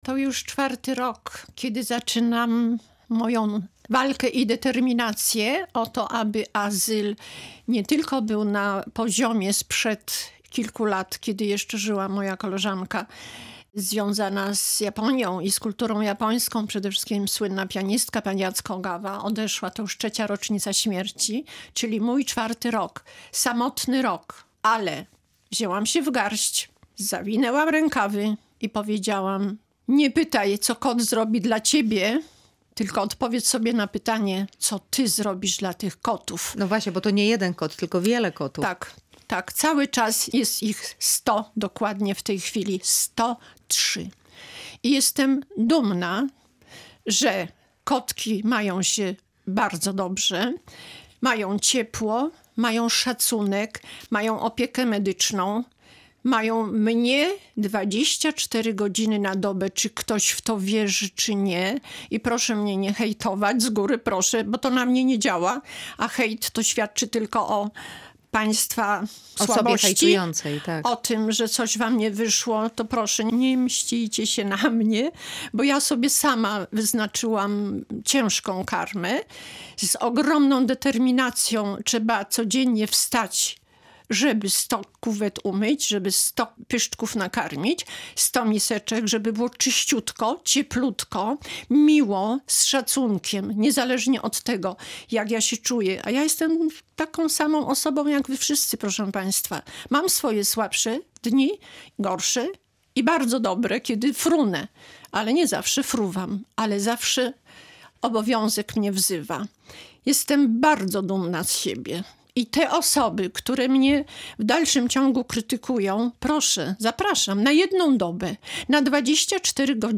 W rozmowie